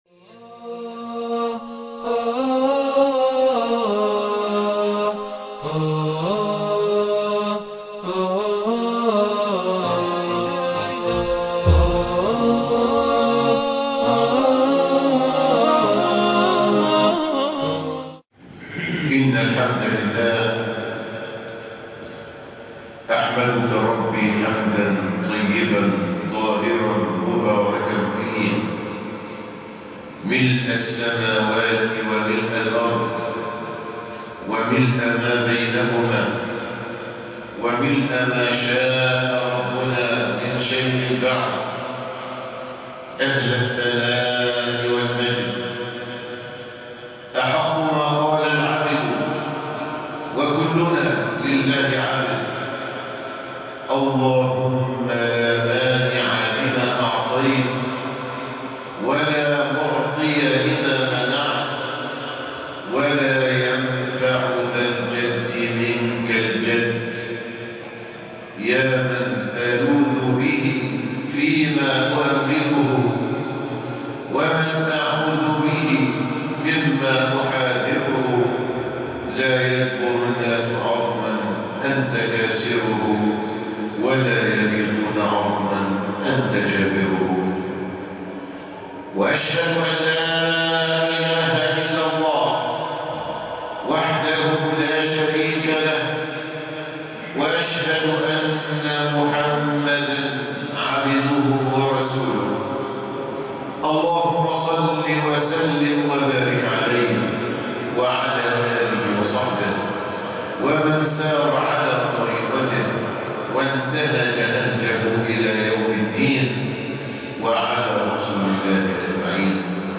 الستر - ..ماهو وكيف تكون مستور ؟ خطب الجمعة